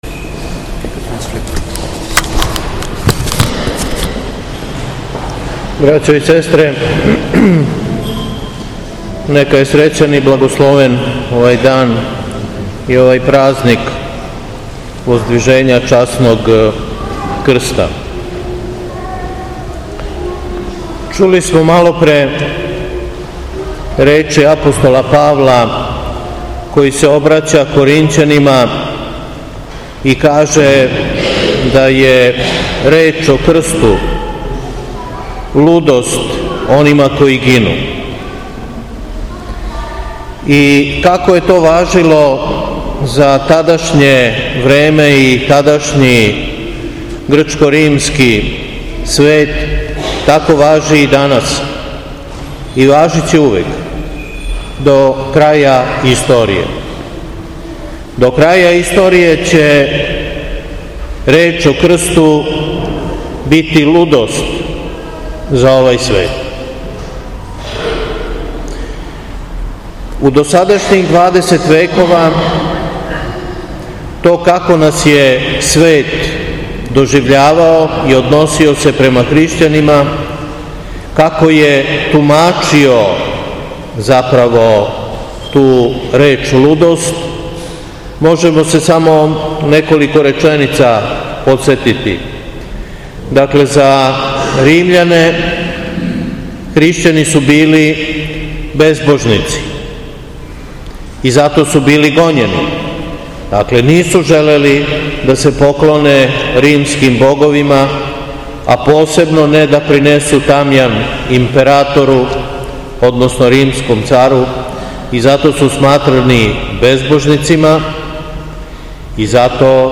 Беседа
На сам дан празника Воздвижења Часног Крста, Митрополит Јован служио је Свету Литургију у Саборном храму Успења Пресвете Богородице у Крагујевцу.